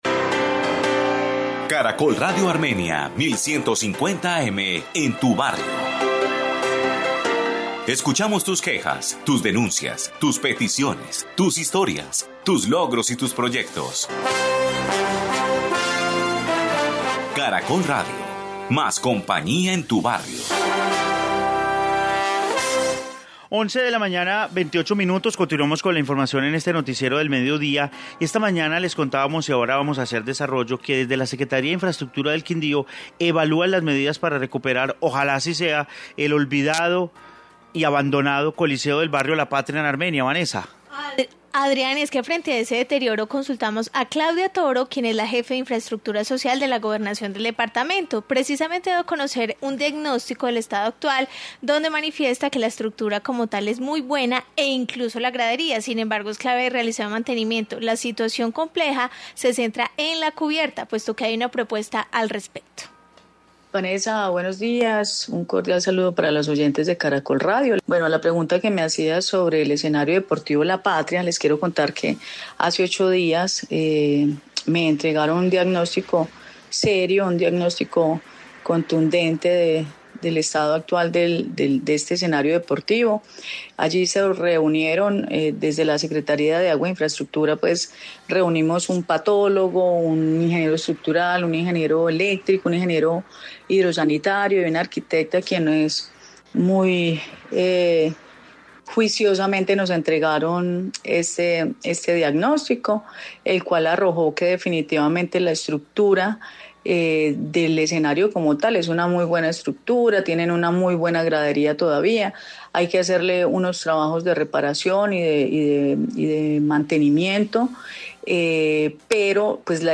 Informe coliseo del barrio La Patria de Armenia